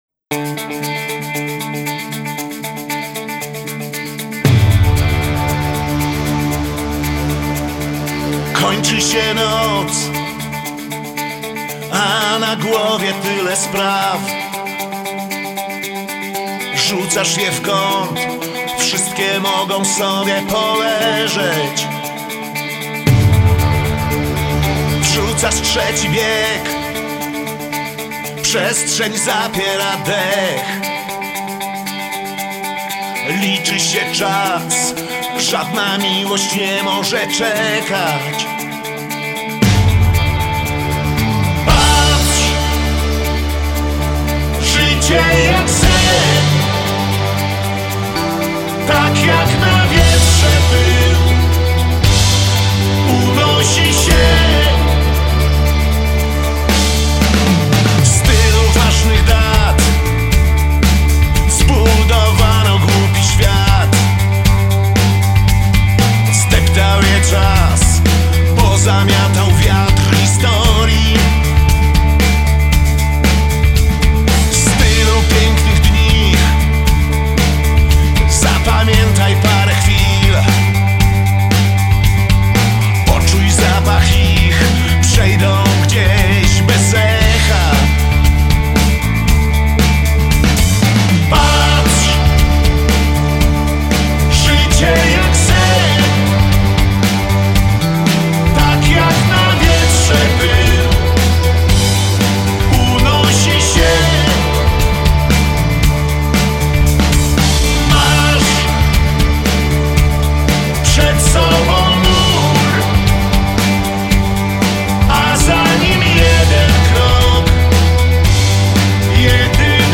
popular Polish rock band